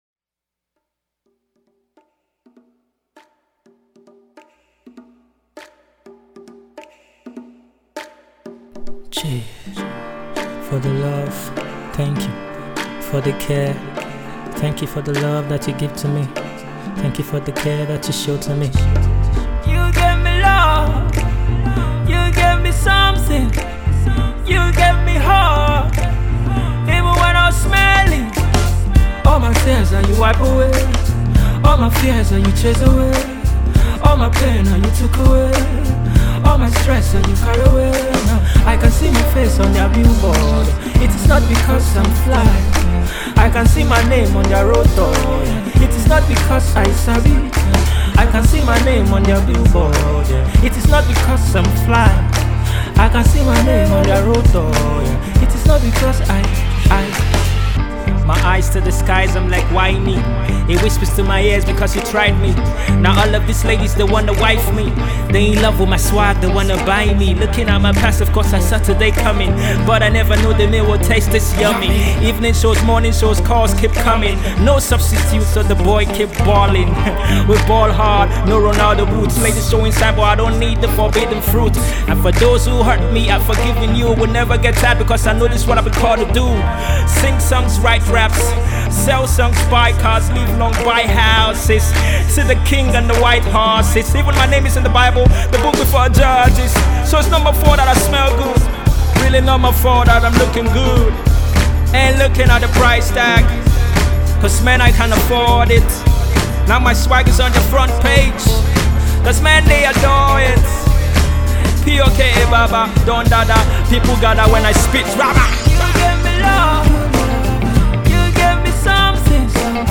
Cover, Rap 0 34…